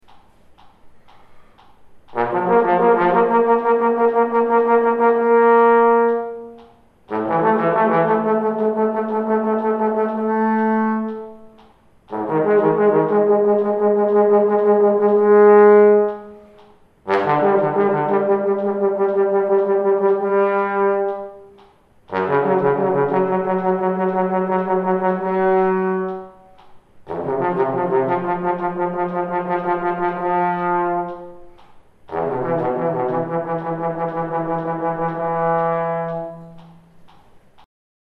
Finally, here is a pattern with lip slurs combined with tonguing:
Flexibility with Articulation
art-flex-edit.mp3